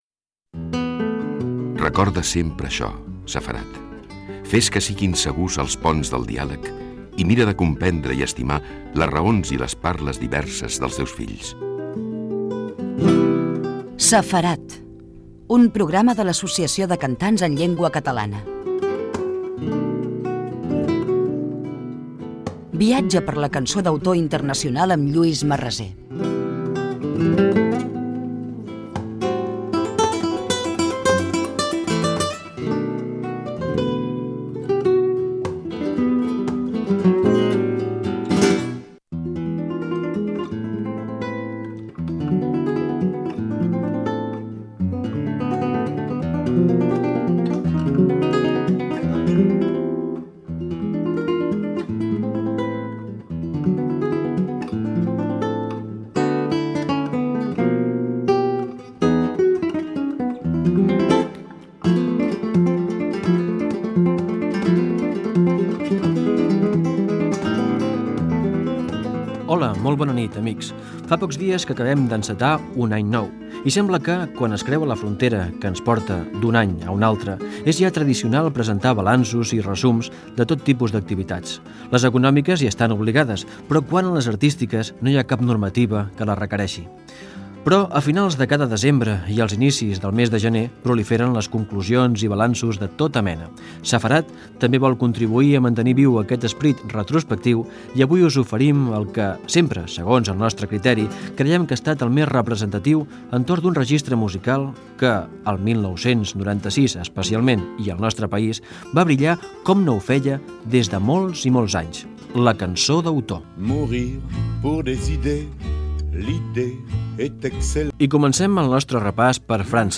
Careta del programa, espai dedicat a fer un viatge per la cançó d'autor internacional.
Musical